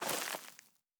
added stepping sounds
Undergrowth_Mono_04.wav